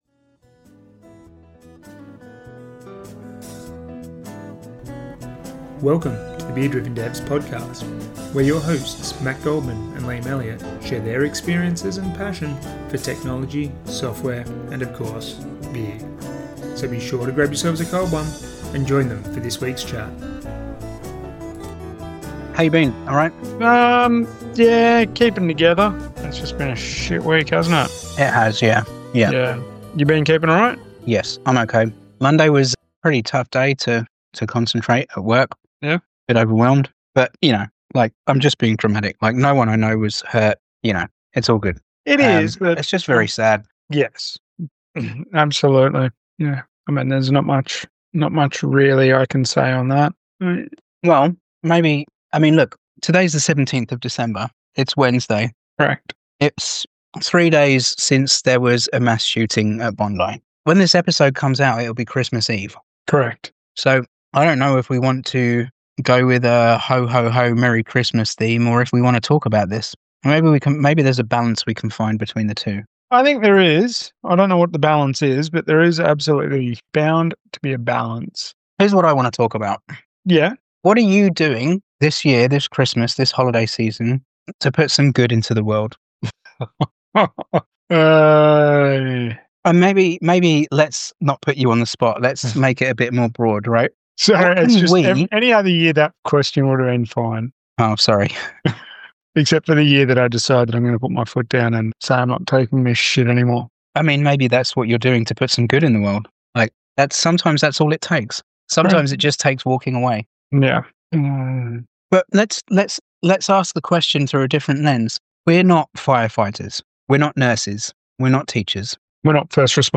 A reflective end-of-year conversation about decency, responsibility, and the small choices that quietly shape our workplaces and communities.
Rather than big gestures or grandstanding, this turns into a thoughtful (and occasionally sweary) discussion about everyday responsibility — at work, in leadership, and in how we treat the people around us.